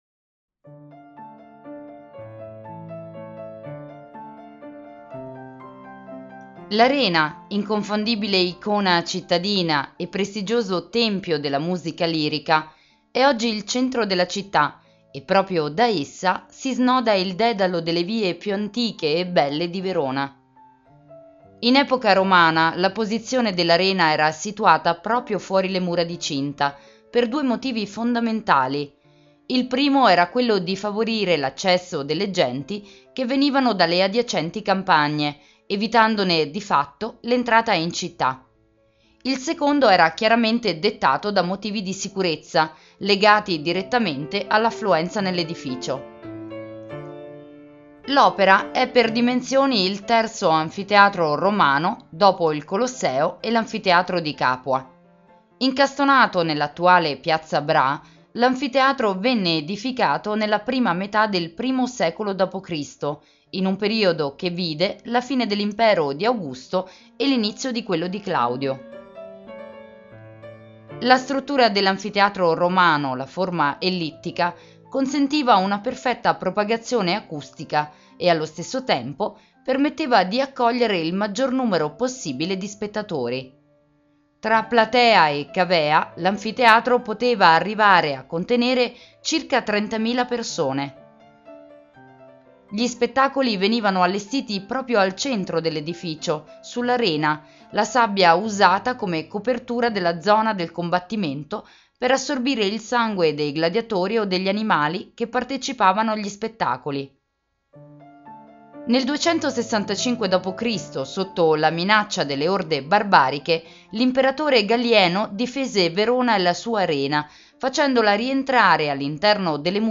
Audioguida Verona - L'Arena - Audiocittà